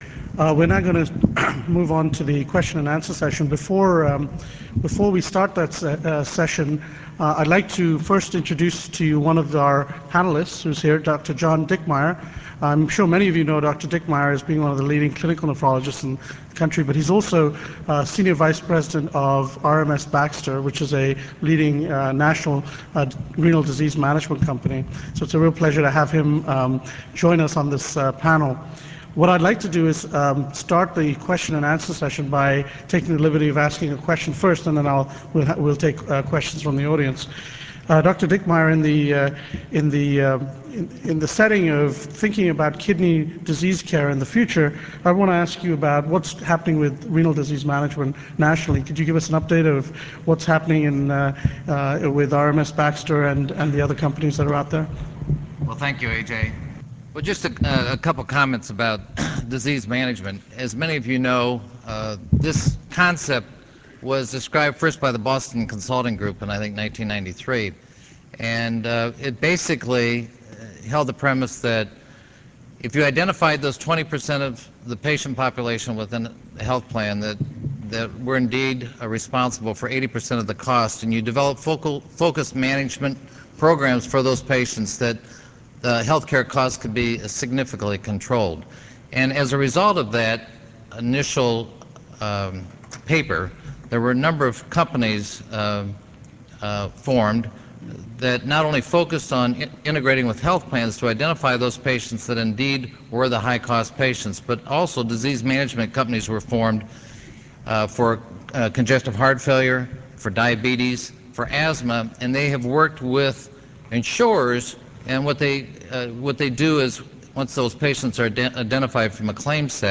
Please make sure that all slide images load before starting the lecture Windows Media Player Option 1 Streaming Server Option 2 For slow connections, right click on preload icon and choose "save target as".